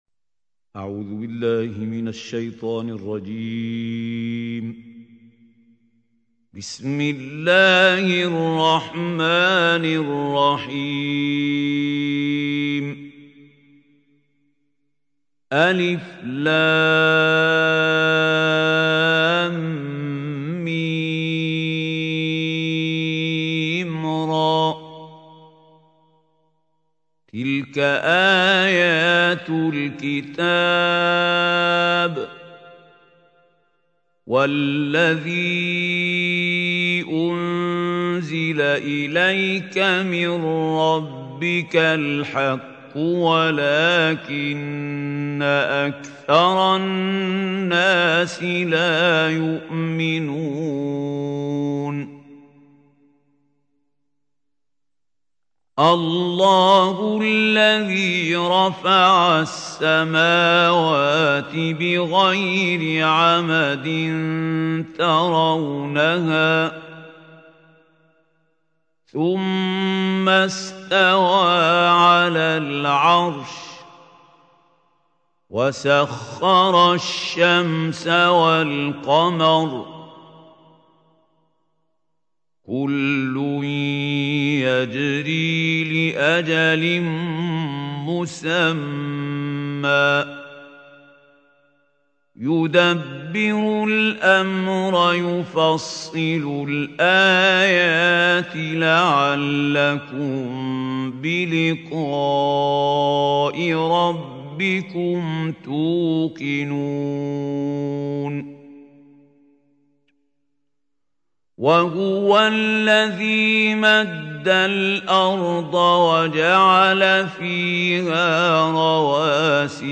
سورة الرعد | القارئ محمود خليل الحصري